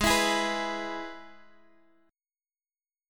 Abm#5 chord